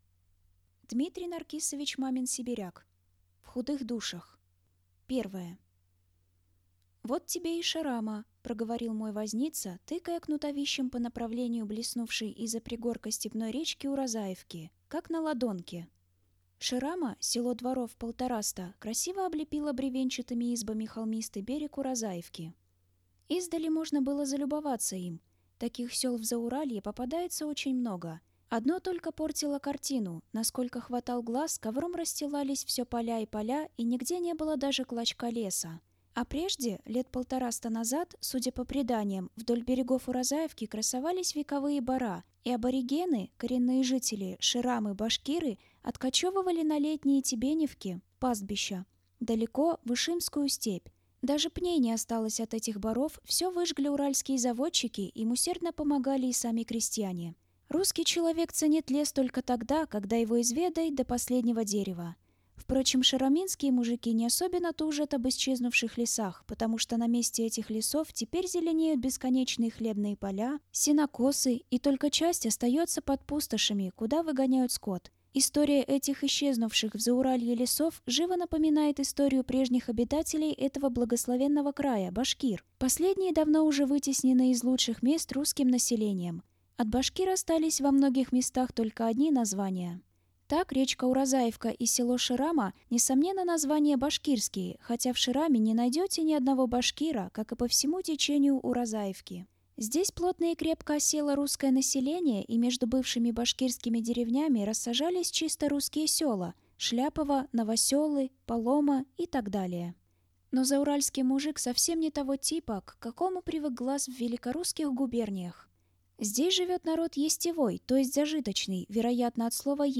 Аудиокнига «В худых душах…»